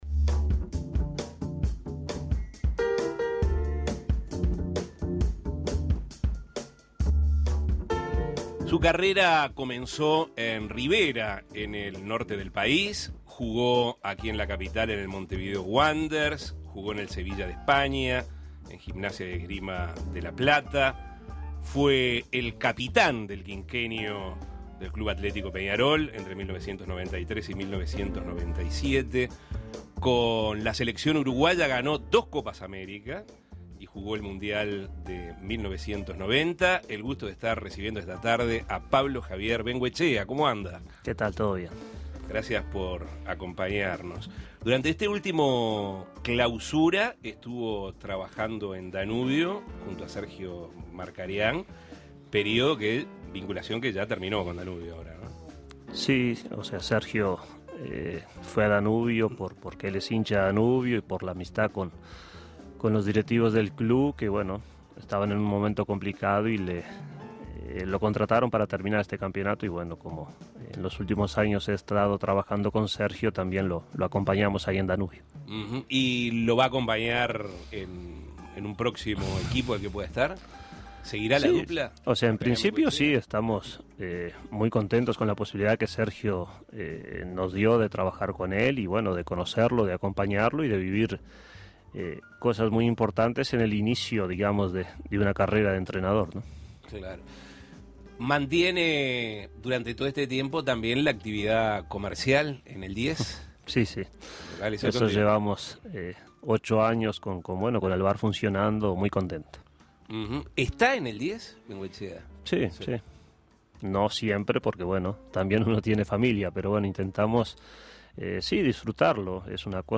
También, fue el capitán del quinquenio del Club Atlético Peñarol entre 1993 y 1997. Escuche al entrevista, en la que dialogó sobre su carrera.